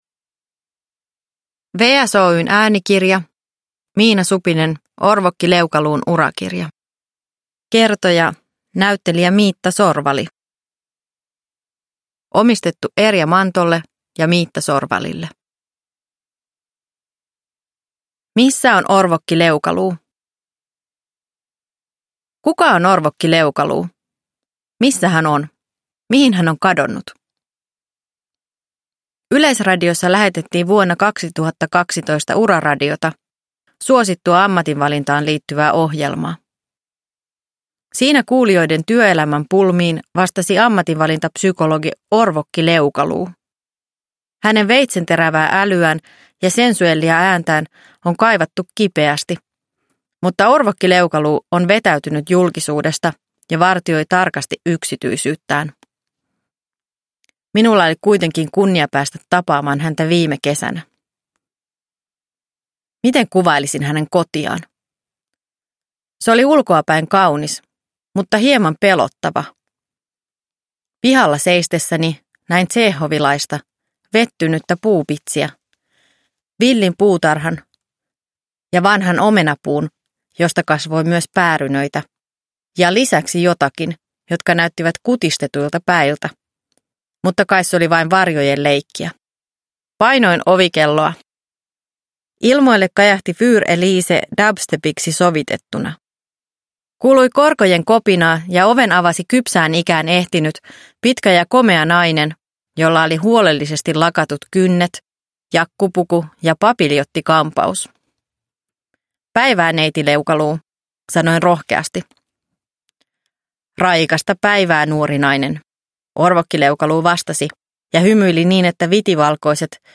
Orvokki Leukaluun urakirja – Ljudbok – Laddas ner
Uppläsare: Miitta Sorvali